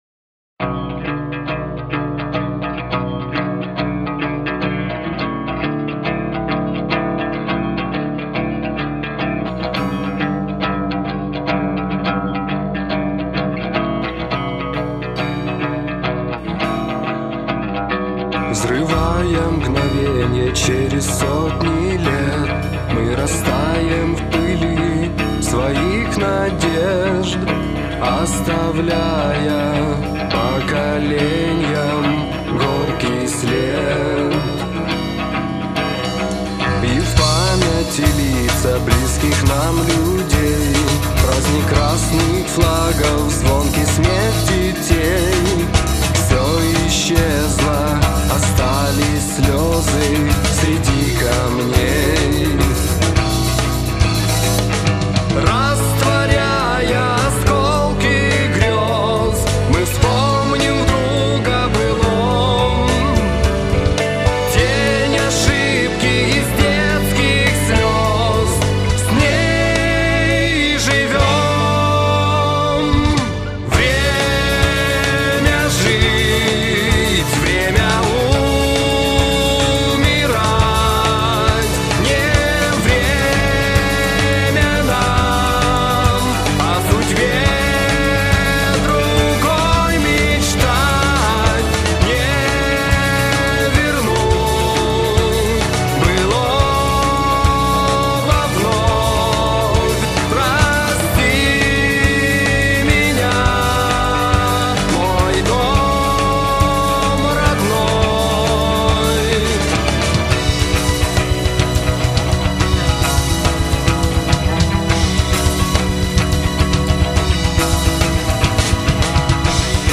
Лирические под гитару